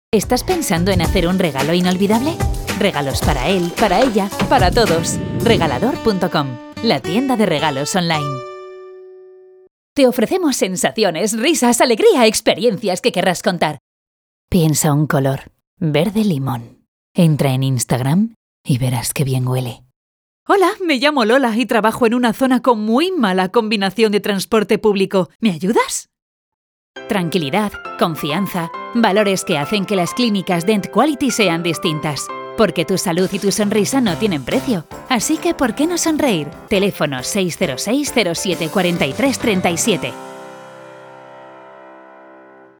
Locutora española 11 años experticia con home studio, disponibilidad, flexibilidad horarios y festivos y rapidez.
kastilisch
Sprechprobe: Werbung (Muttersprache):
Voice over freelance with home studio pro.